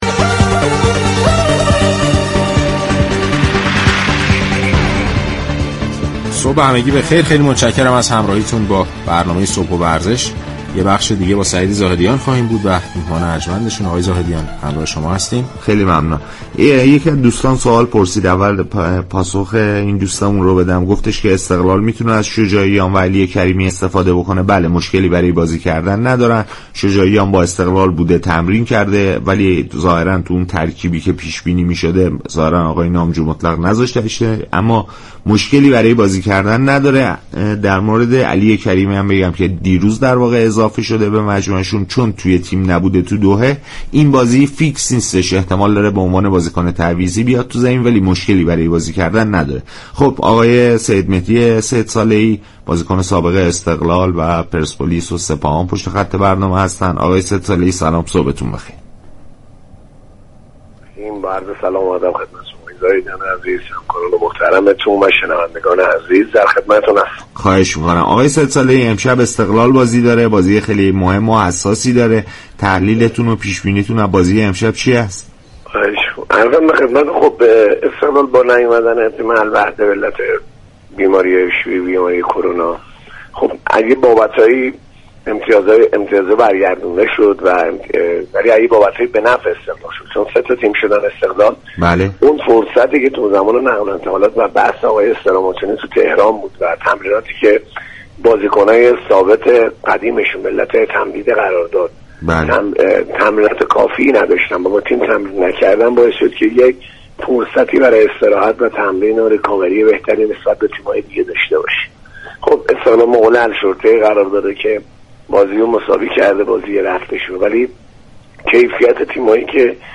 شما می توانید از طریق فایل صوتی پیوست بطور كامل شنونده این گفتگو باشید.
برنامه صبح و ورزش ساعت 7:10 هر روز به جز جمعه ها به مدت یك ساعت و20 دقیقه از شبكه رادیویی ورزش تقدیم شنوندگان می شود.